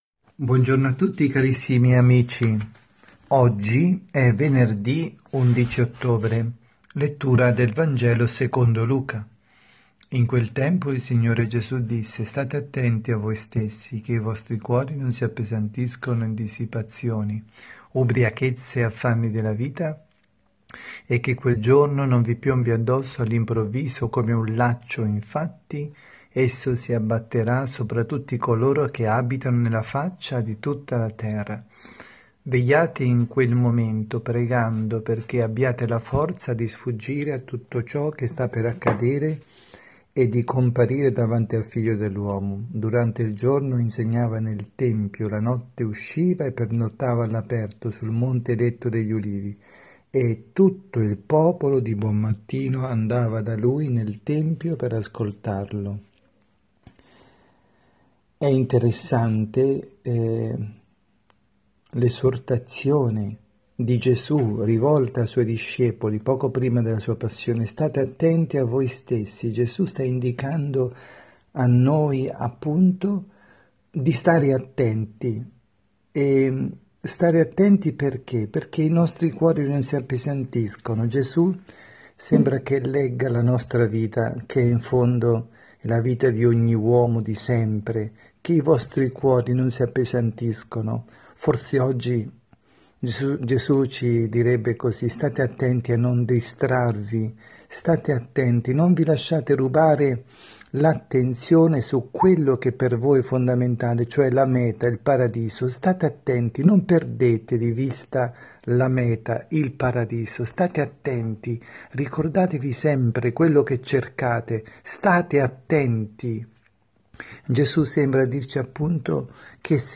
avvisi, Omelie
2019-10-11_Venerdi_pMG_Omelia_dalla_Casa_di_riposo_S_Marta_Milano